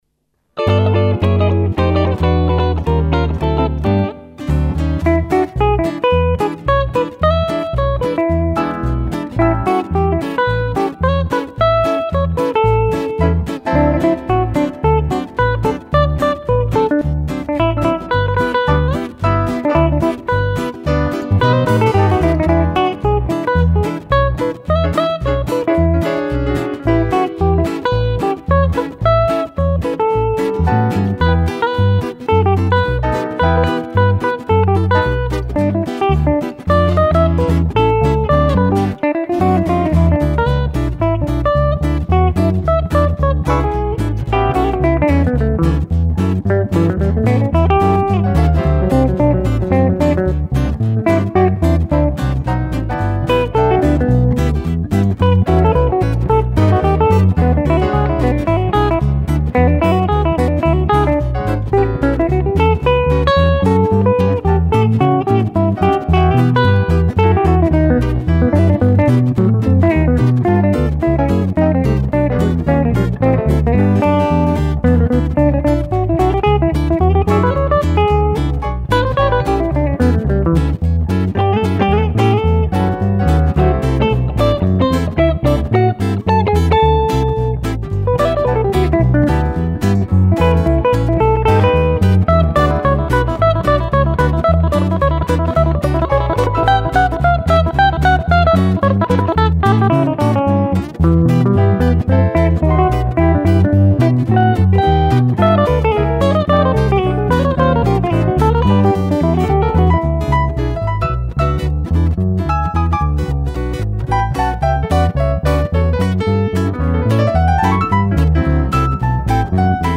Chitarre e Basso
Pianoforte e Vibrafono
Batteria